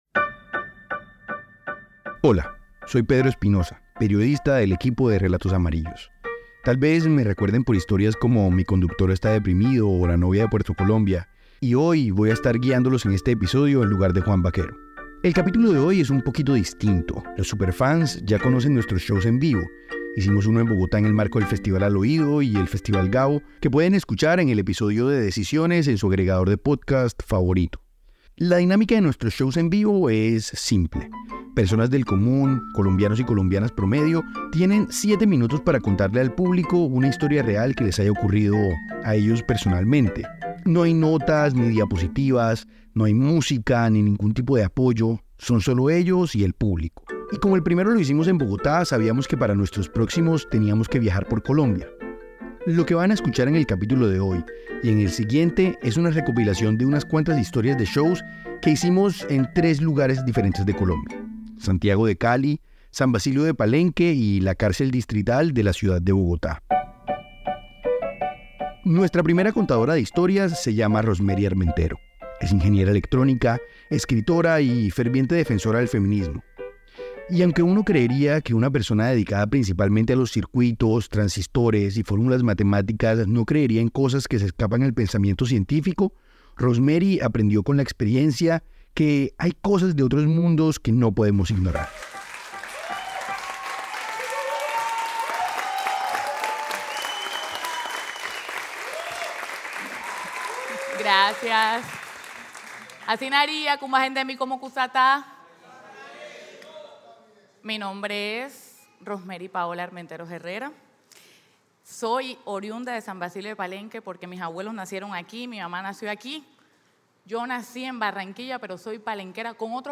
..Pódcast. En este episodio de Relatos amarillos recopilamos historias destacadas de nuestros shows de storytelling en Bogotá, Cali y Palenque.